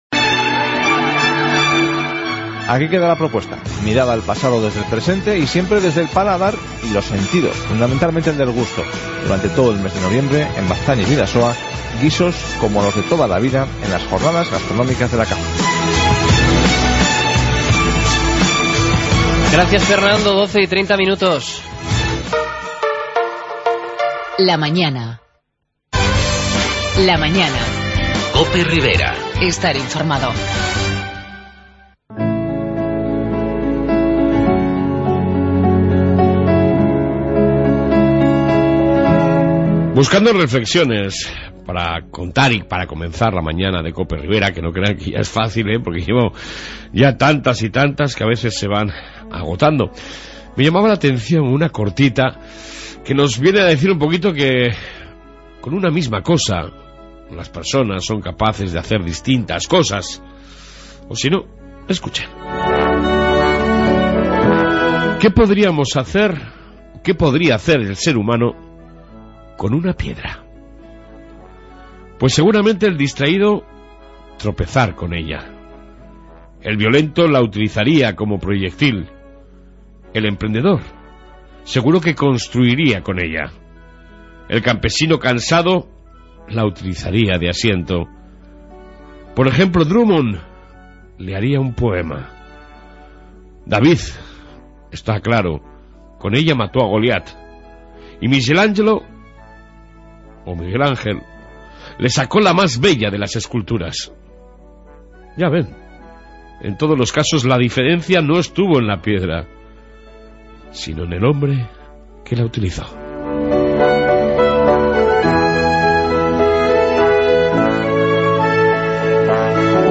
AUDIO: En esta 1 parte Reflexión diaria, Informe Policía Municipa, entrevista sobre las nuevas tecnologias aplicadas en Peralta y Muchas...